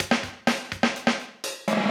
PERCLOOP108_HOUSE_125_X_SC2.wav